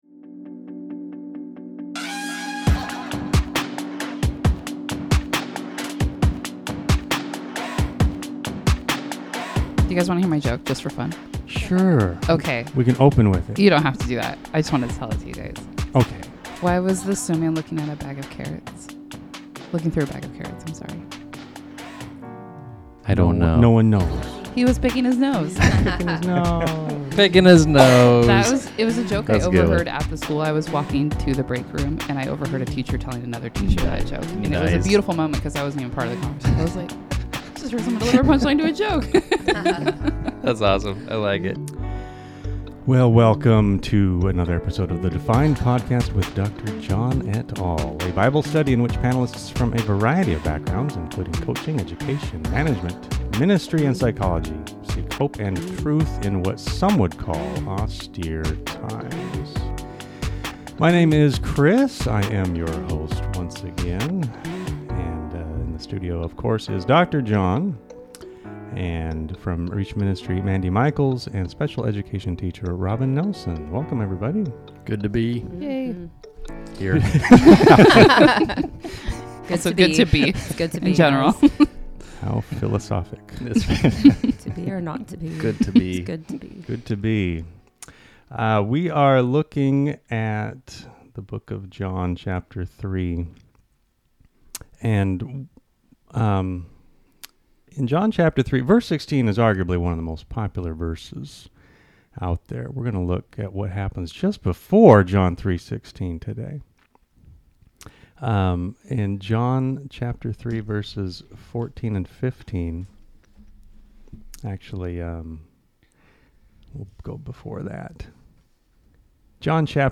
S2 E5 | "Lifted" | Bible Study John 3:13-15
Panelists discuss the parallels between these two events and their relevance for today.